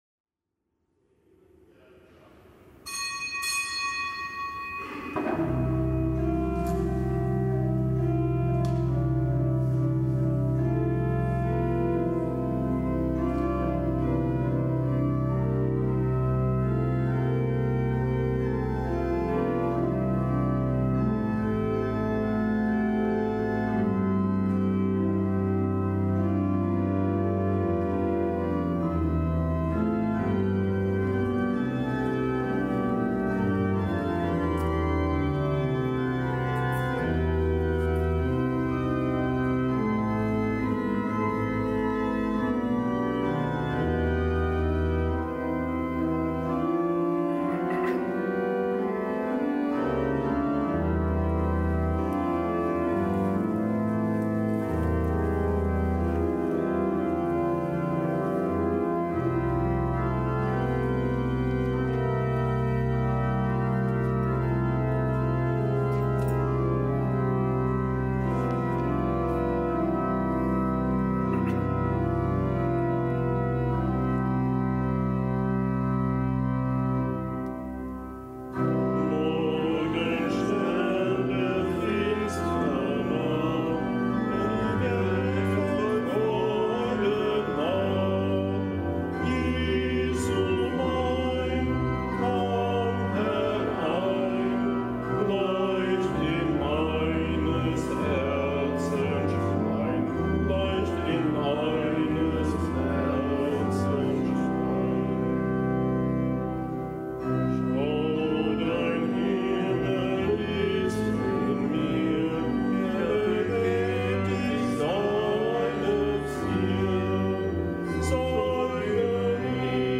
Kapitelsmesse aus dem Kölner Dom am Mittwoch der zweiunddreißigsten Woche im Jahreskreis. Zelebrant: Weihbischof Rolf Steinhäuser.